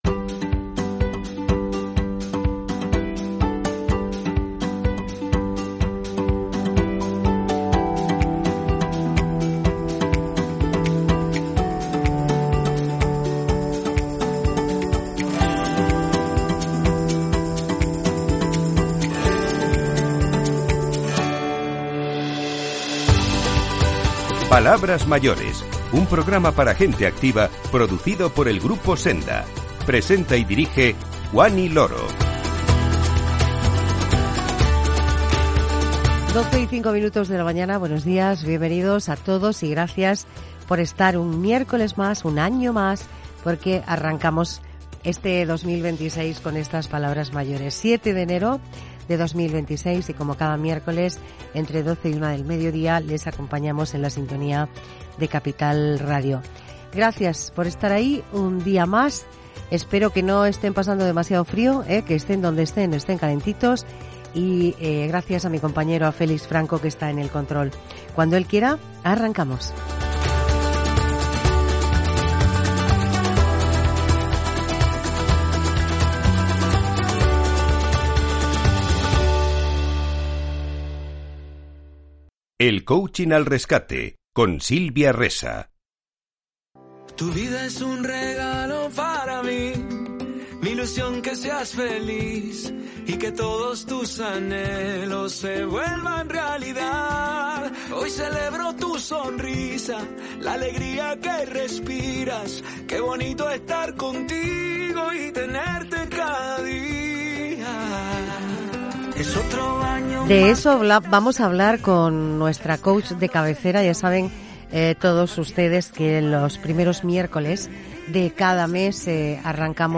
El psicólogo Javier Urra es uno de los protagonistas del programa de hoy, preguntándonos: ¿Cómo somos realmente?